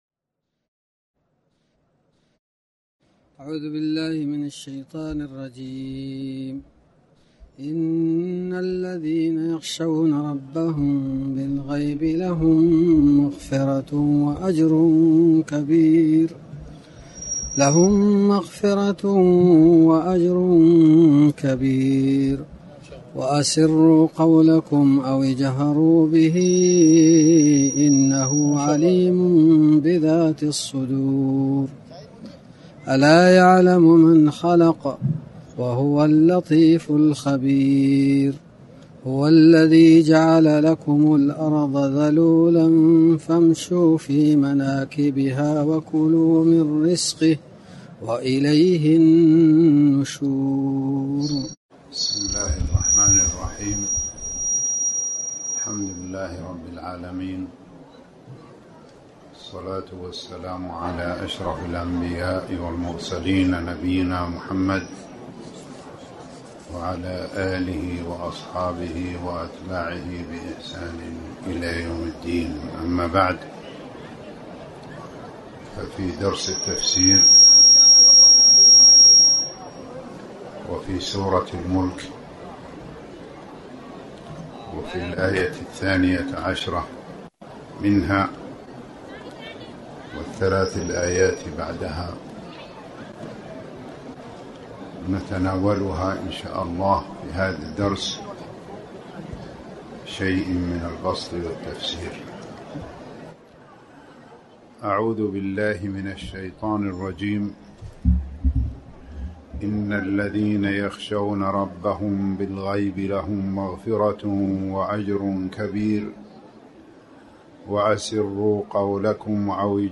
تاريخ النشر ٣ صفر ١٤٣٩ هـ المكان: المسجد الحرام الشيخ